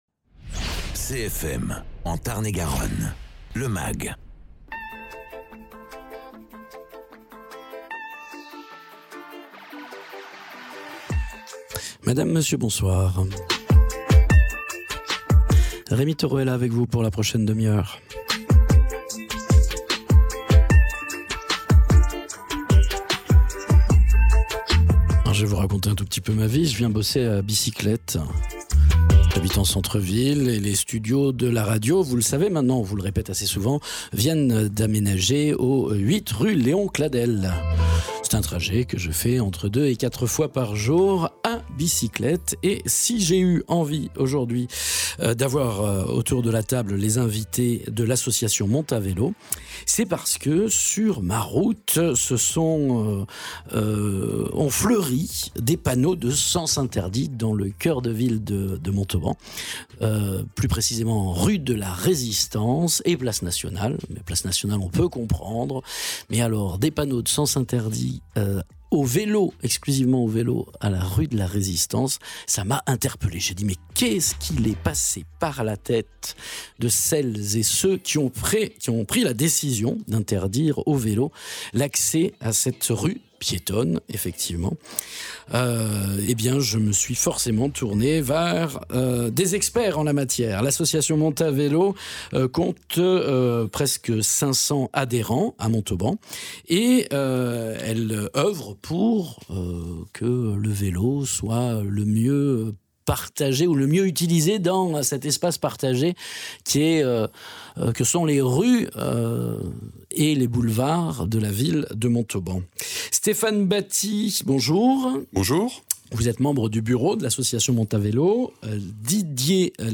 L’association Montavélo proposera bientôt des séances de vélo-école. Dans nos studios trois membres de l’association en parle et évoquent également un arrêté de la municipalité de Montauban qui interdit l’accès à la rue de la résistance aux cyclistes...